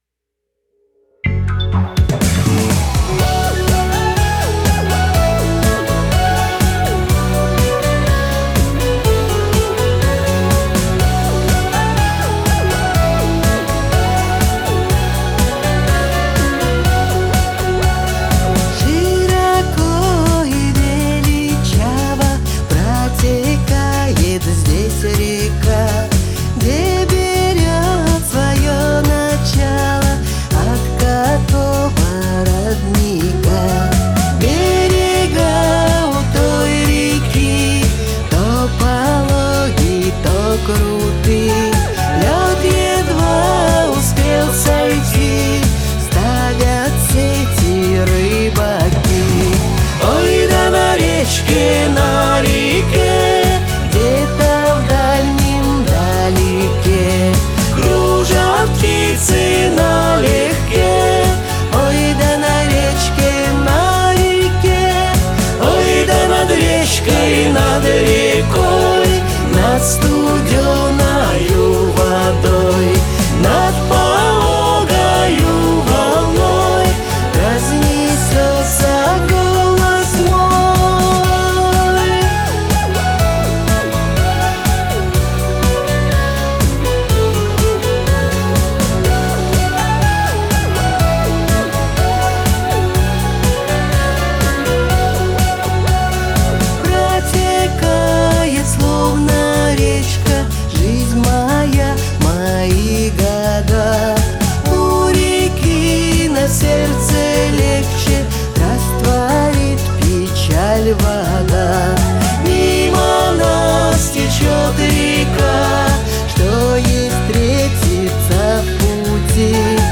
гитары, бэк-вокал